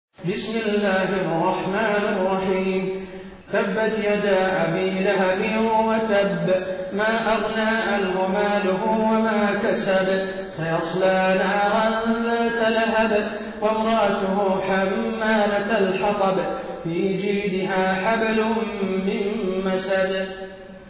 taraweeh-1433-madina